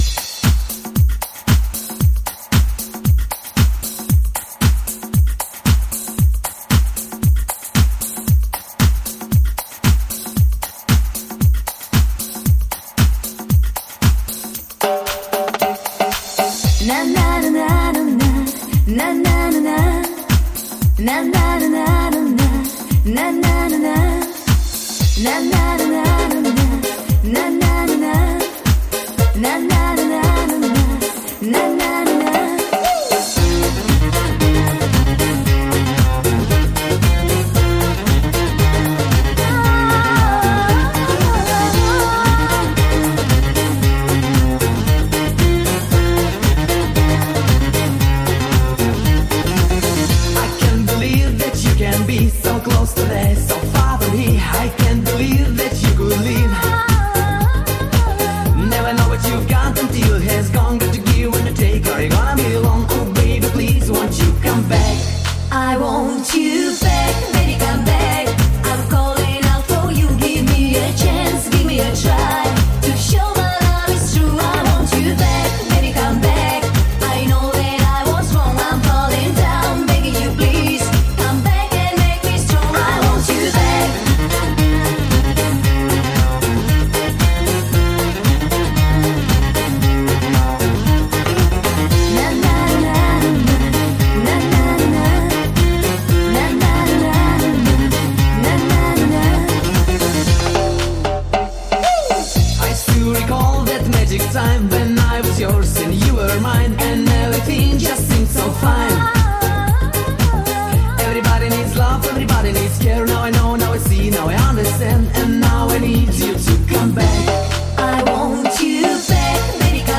Genre: Dance.